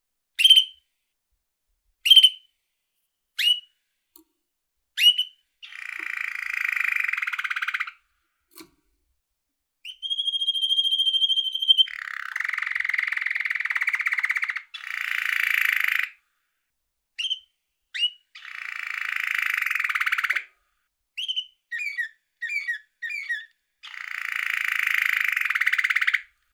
canary trill yellow - bird cage.wav
little yellow canary playing, trilling and chirping in his birdgage.
.WAV .MP3 .OGG 0:00 / 0:27 Type Wav Duration 0:27 Size 4,46 MB Samplerate 44100 Hz Bitdepth 1411 kbps Channels Stereo little yellow canary playing, trilling and chirping in his birdgage.
canary_trill_yellow_2w6_6fd.ogg